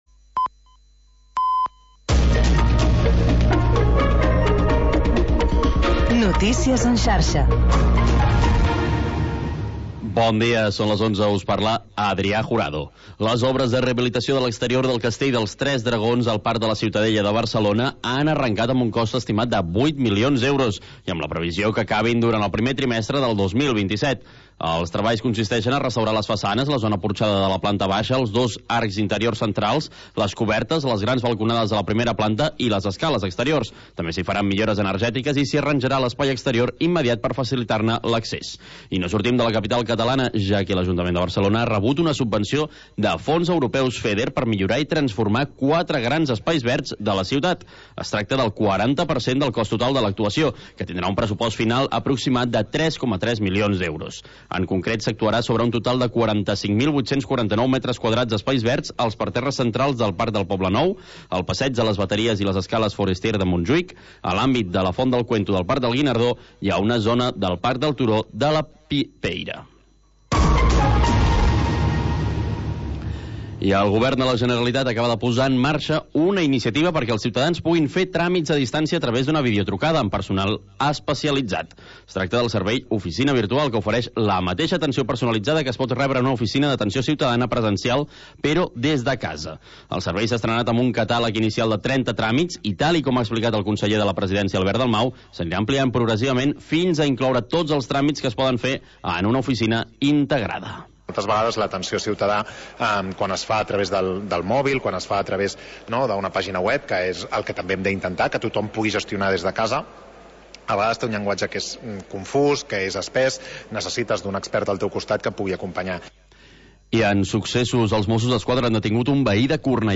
Havanera, cant de taverna i cançó marinera. obrint una finestra al mar per deixar entrar els sons més mariners